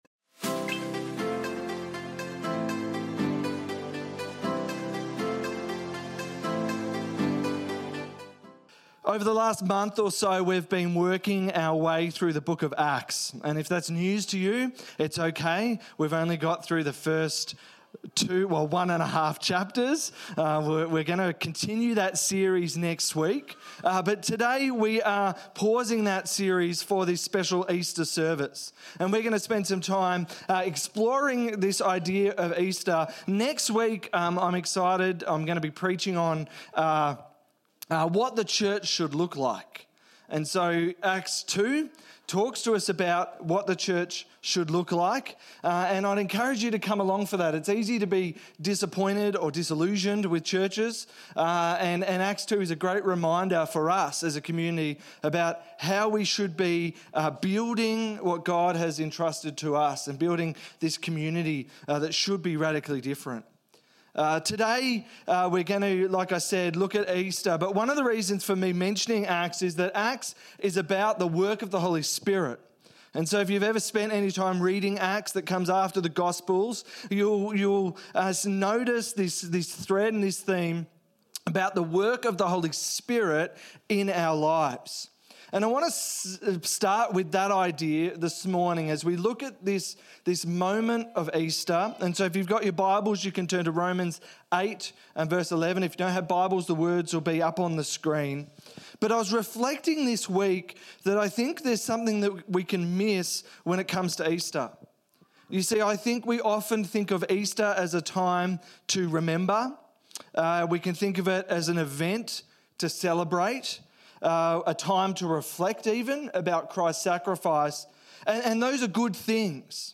Easter Sunday 2023